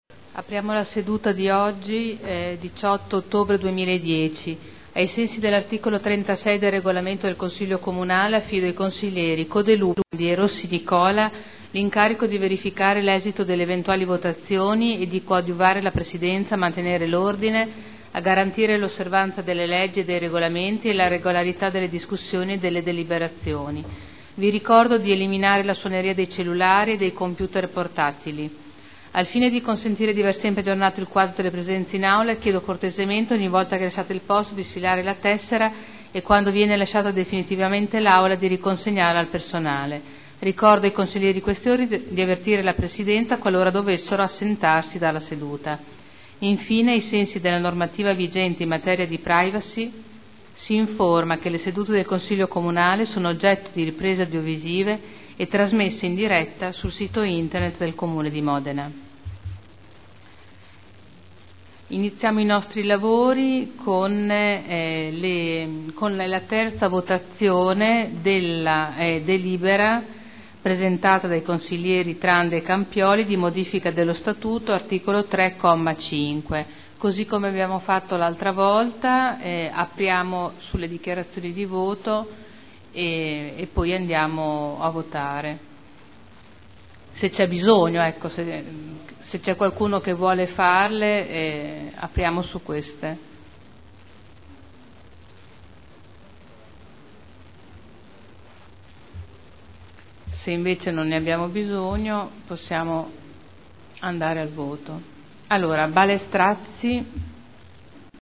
Seduta del 18 ottobre 2010. Il Presidente Caterina Liotti apre il Consiglio Comunale